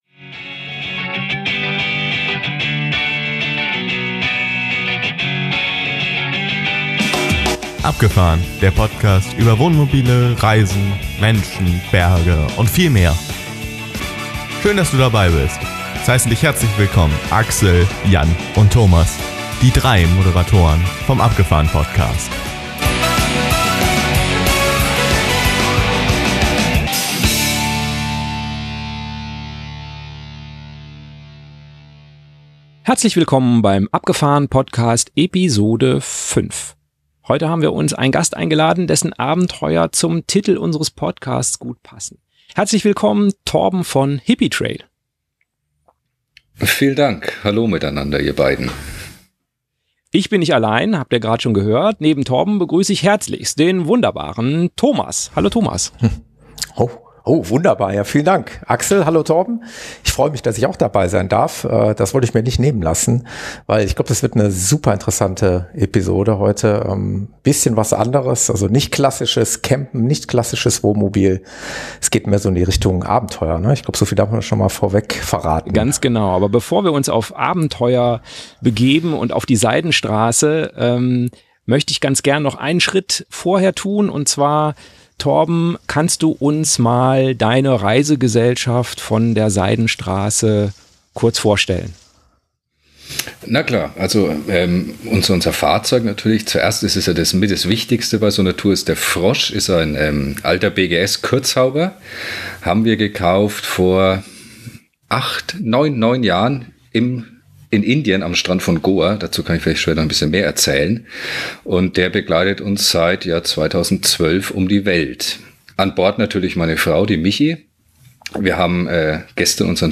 Interview: Abenteuer Freiheit - als Familie auf der Seidenstraße ~ Abgefahrn-Podcast - Wohnmobile, Camping, Reisen Podcast